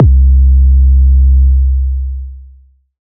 C-EDMBass-1.wav